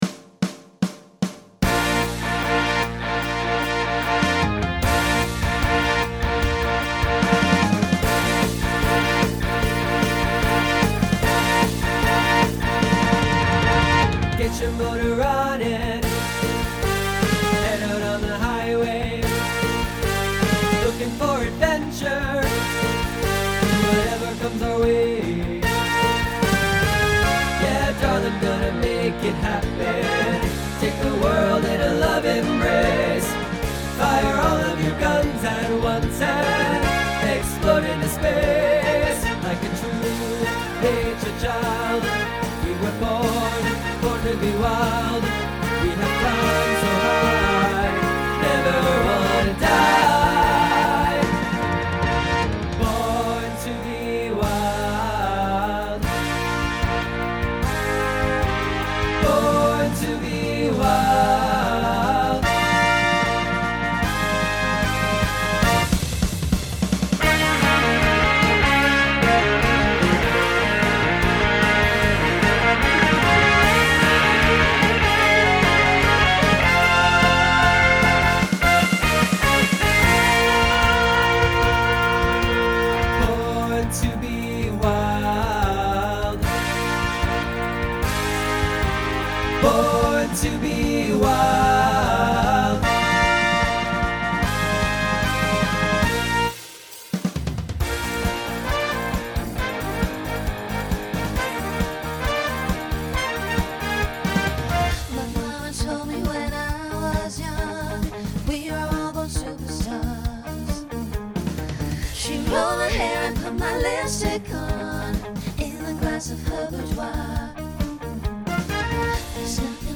Pop/Dance , Rock
Transition Voicing Mixed